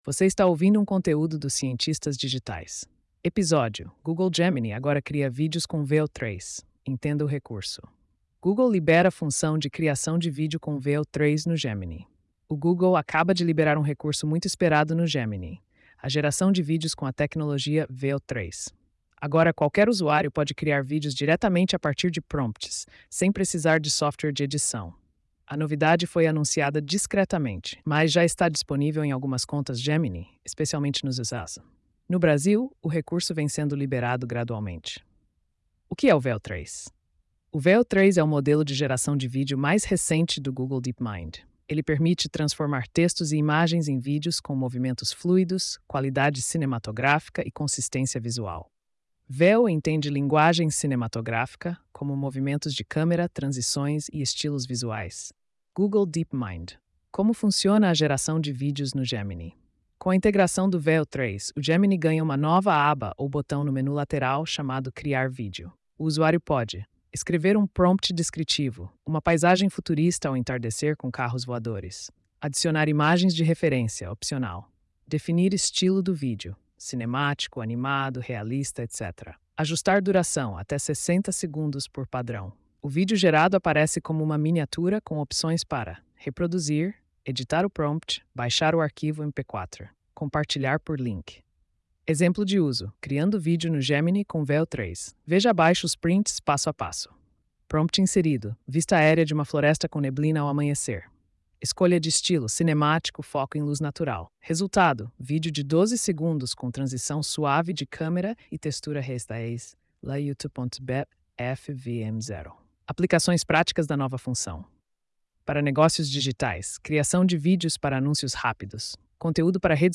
post-3328-tts.mp3